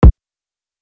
NEW CHAINS, SAME SHACKLES KICK.wav